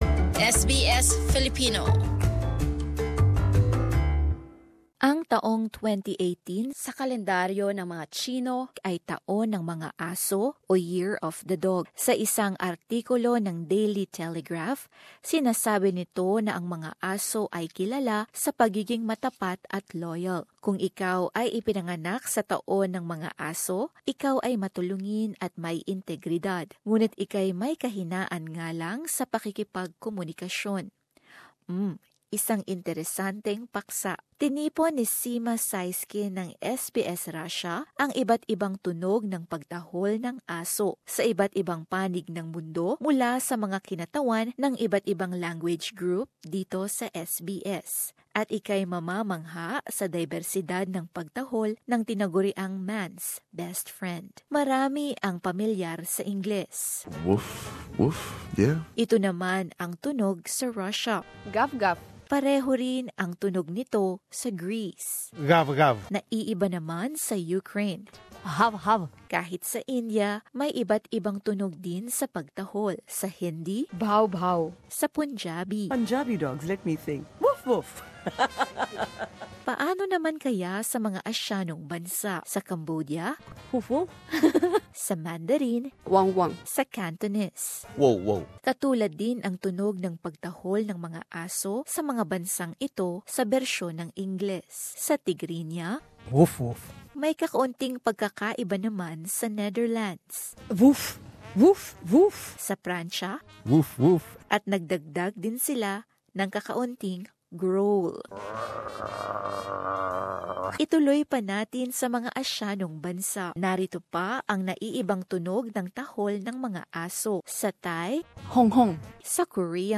Ikaw ay tiyak na mamamangha sa iba’t iba at magkakaparehong tunog ng pagtahol ng mga aso sa buong mundo.